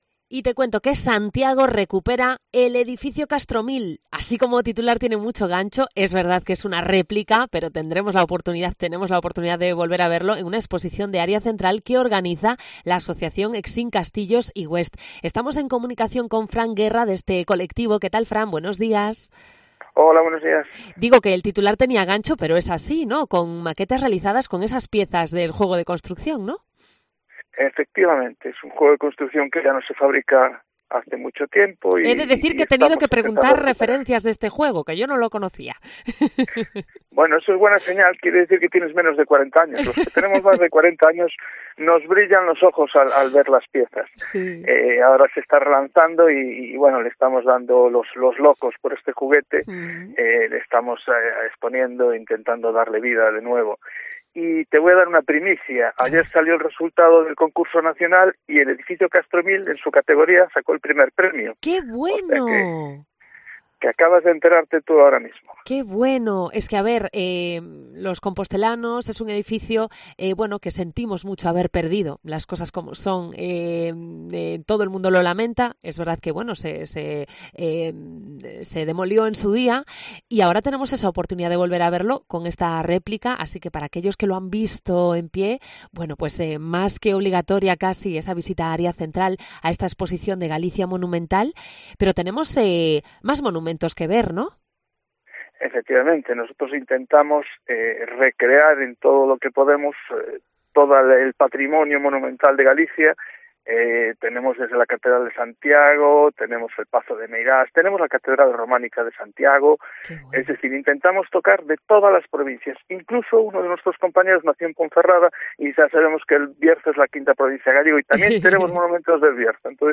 Recorrido radiofónico por Galicia Monumental, exposición de edificios realizados con piezas de Exin